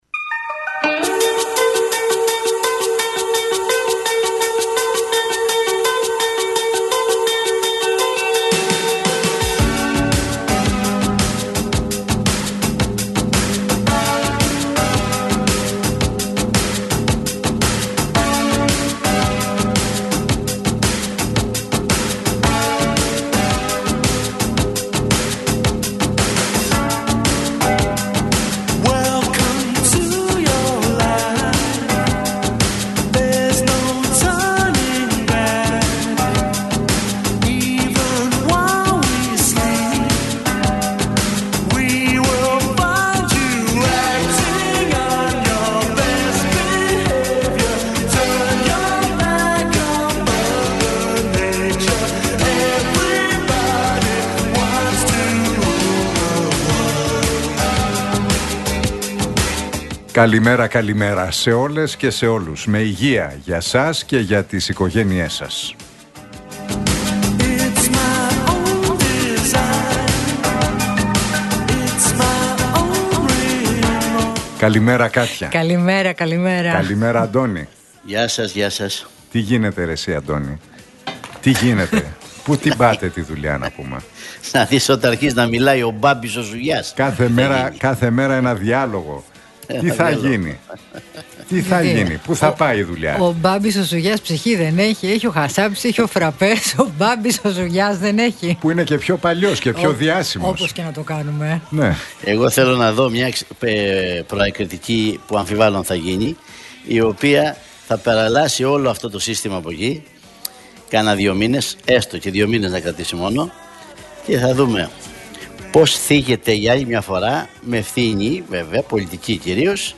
Ακούστε την εκπομπή του Νίκου Χατζηνικολάου στον ραδιοφωνικό σταθμό RealFm 97,8, την Τετάρτη 2 Ιουλίου 2025.